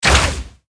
archer_skill_towshot_02_fire.ogg